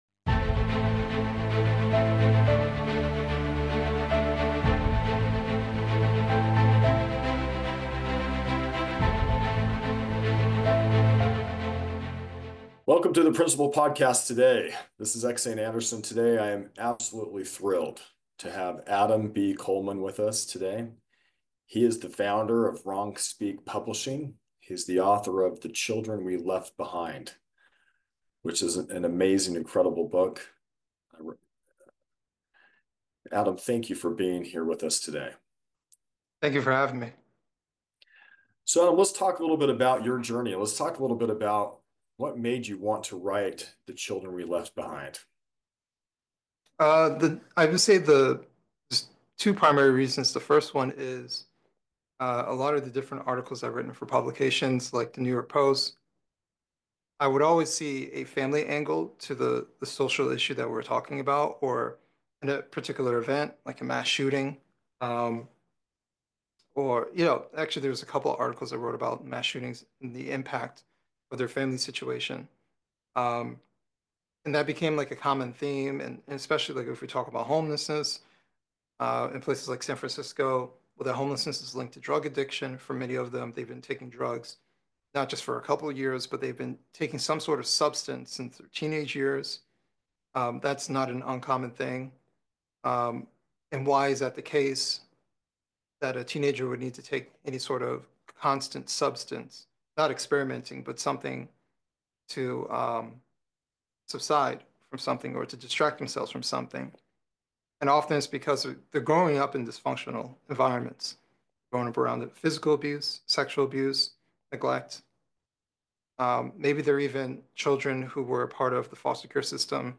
Thinking of Breaking Up Your Family? Think Again. An Interview